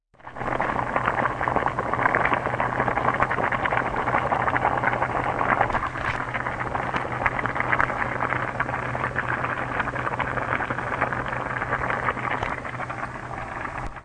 随机的" 水壶泡开水愚蠢的关闭
描述：水壶泡沫沸腾的水愚蠢close.flac
Tag: 发泡 水壶 煮沸